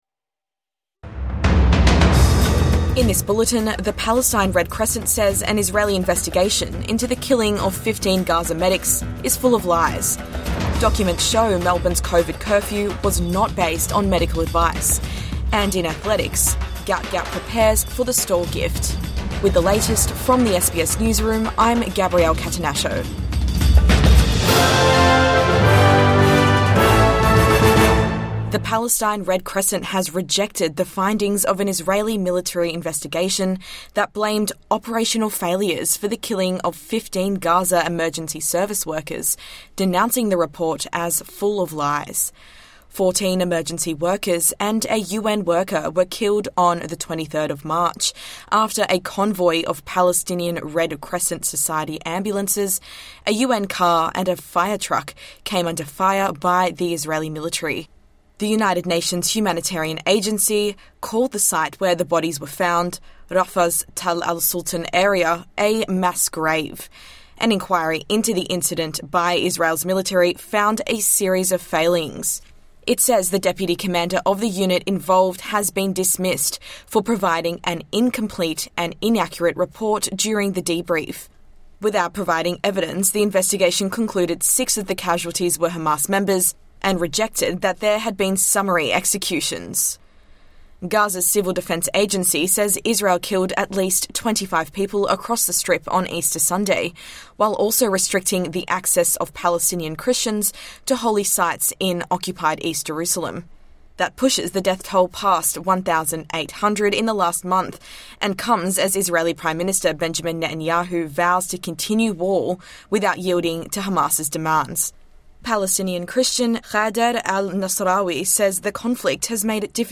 Red Crescent rejects report into Medics' death | Morning News Bulletin 21 April 2025